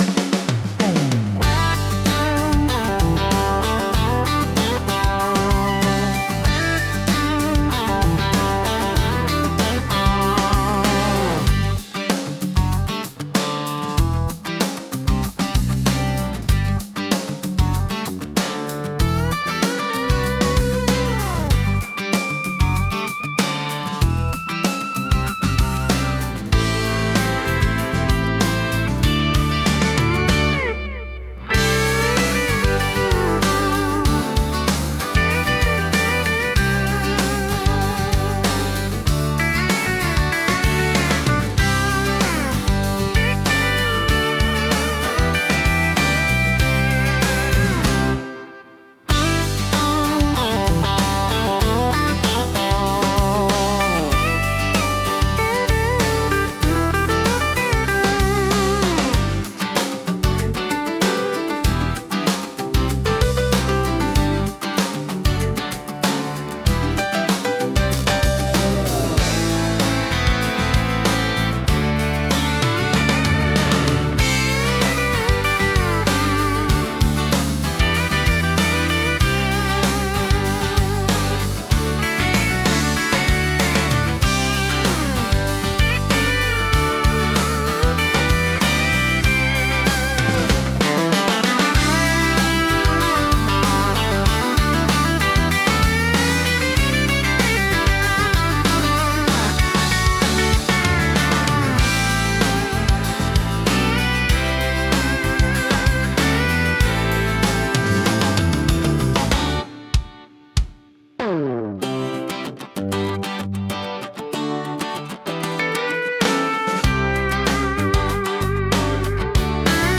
聴く人に快適で穏やかな気持ちをもたらし、ナチュラルで親近感のある空気感を演出します。